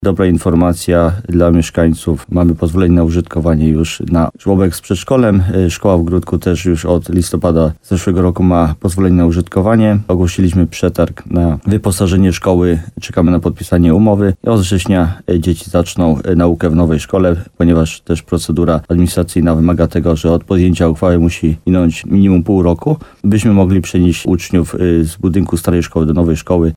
Wójt gminy Grybów Jacek Migacz przypomina, że decyzja o budowie była poprzedzona dokładnymi analizami demograficznymi.